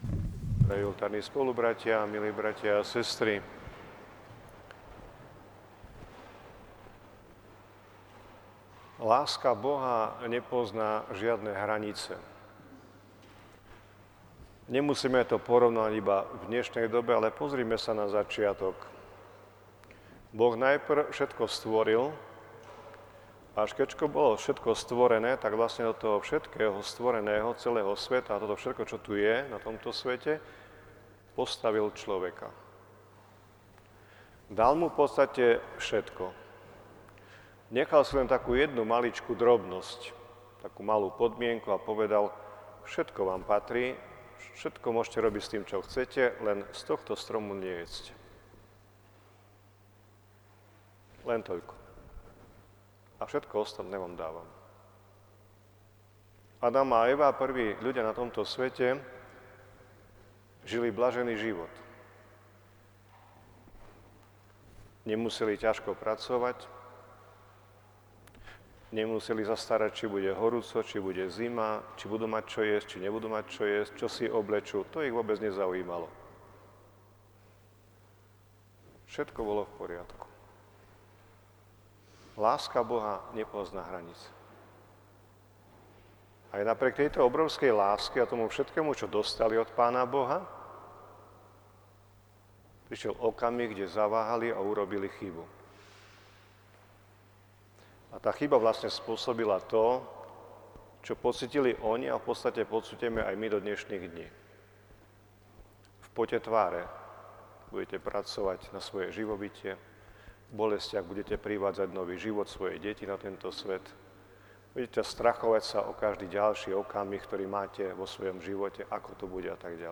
Sobota 25. novembera sa v bazilike niesla v duchu modlitieb pri relikviách blahoslaveného Metoda Dominika Trčku.